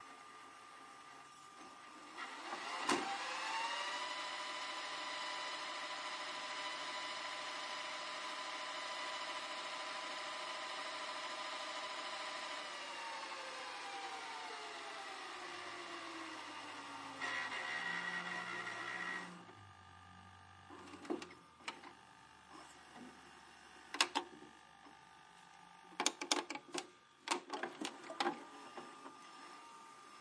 旧的VHS机器快速转发和快速倒带。